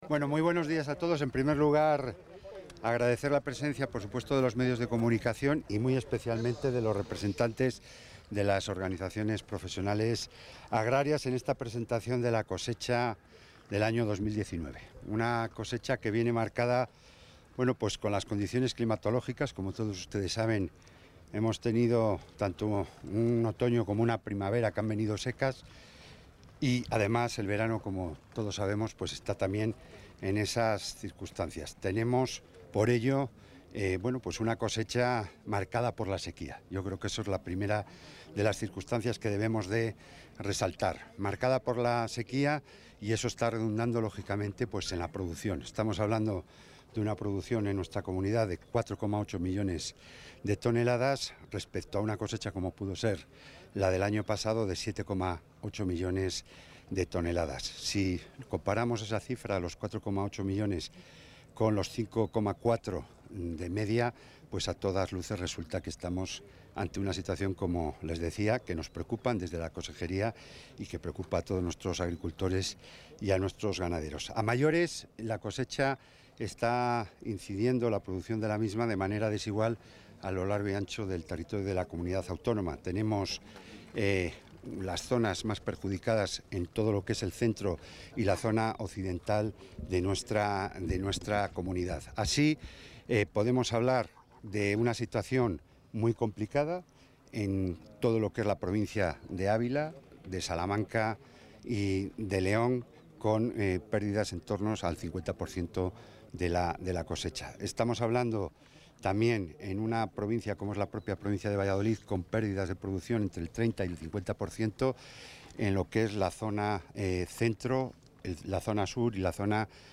Intervención del consejero.
El consejero de Agricultura, Ganadería y Desarrollo Rural, Jesús Julio Carnero, ha dado a conocer esta mañana en el municipio vallisoletano de Valoria la Buena los datos de una cosecha en estado avanzado en la Comunidad.